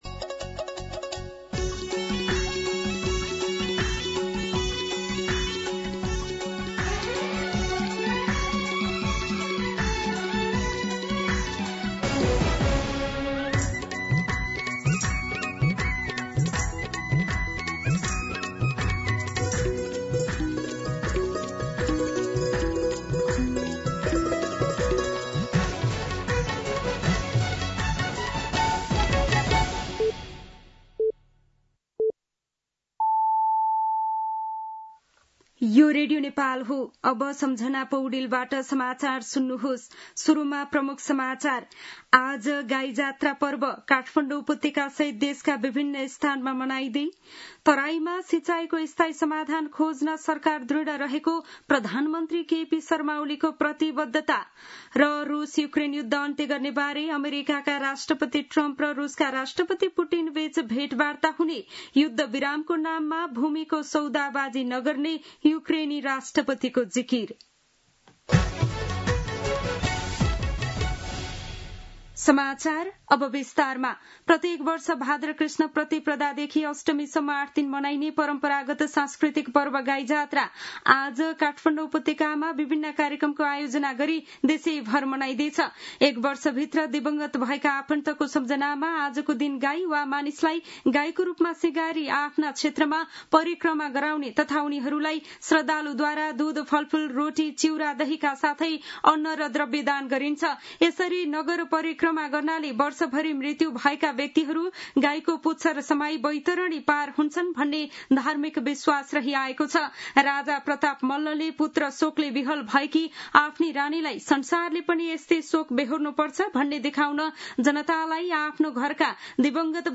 दिउँसो ३ बजेको नेपाली समाचार : २५ साउन , २०८२
3-pm-Nepali-News-3.mp3